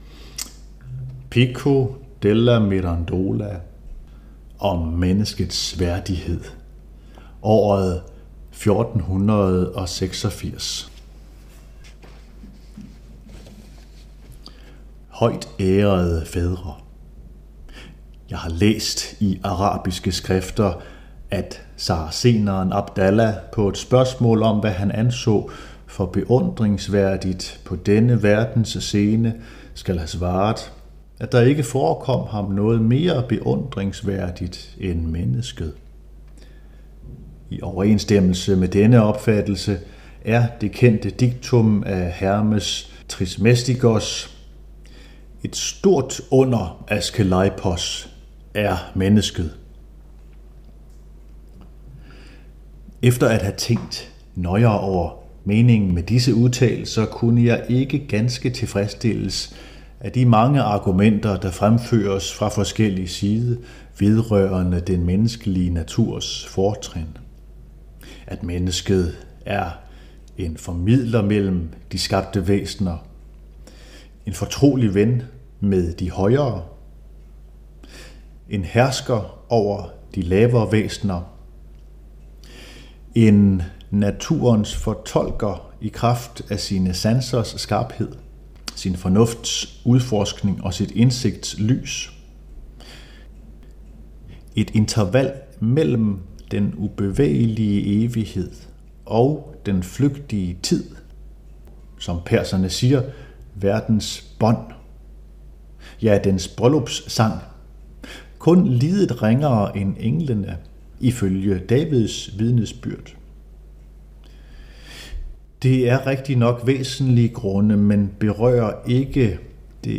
læser uddrag